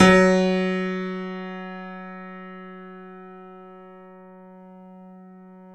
Index of /90_sSampleCDs/Roland L-CD701/KEY_YC7 Piano ff/KEY_ff YC7 Mono